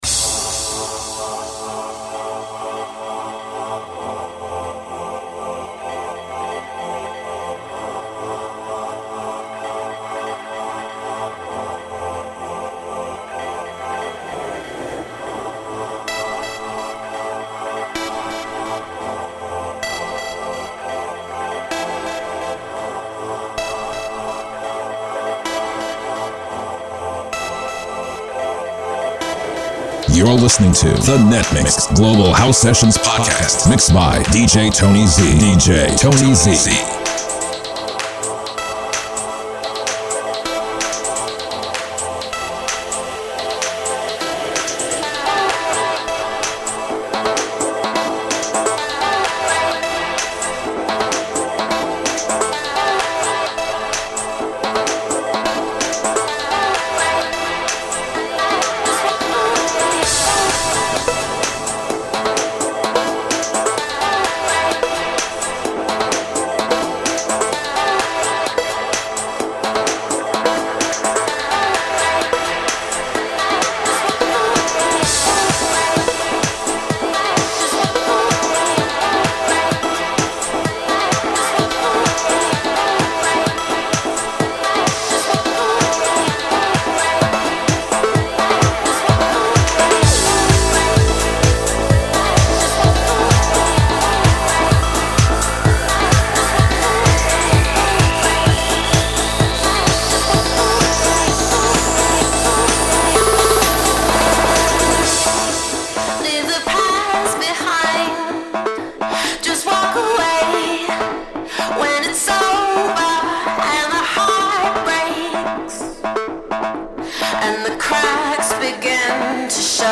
there are some great house classics in the mix